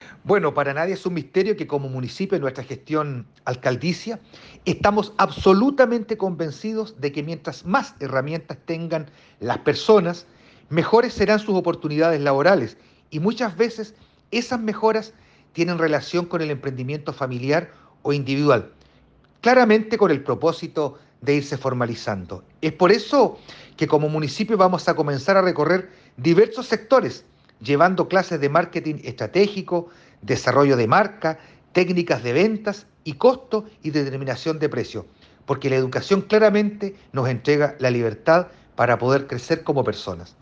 Al respecto, el alcalde de Castro, Juan Eduardo Vera, enfatizó en que este municipio se ha caracterizado por el fuerte énfasis en capacitar a vecinas y vecinos, para mejorar su calidad de vida.
Cuna-alcalde-JEV-Caravana-del-emprendimiento.mp3